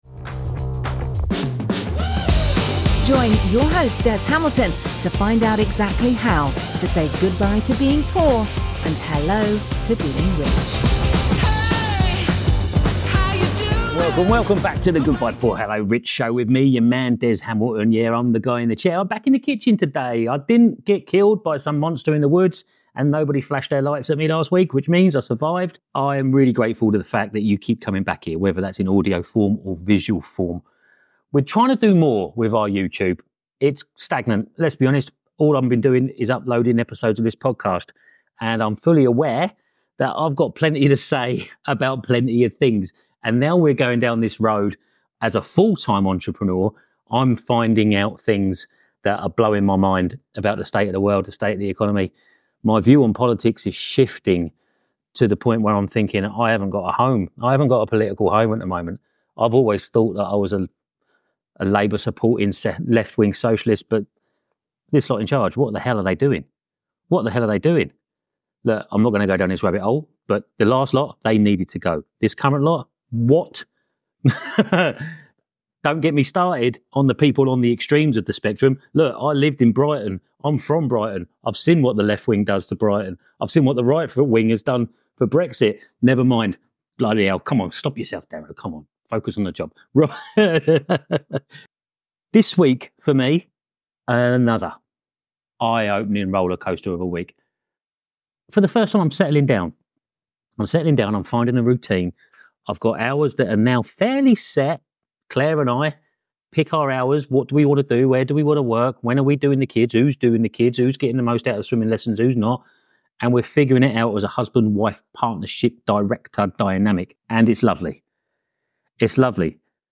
This week, I’m in the kitchen again—no monsters from the woods got me, and I’m still here with another packed episode for you!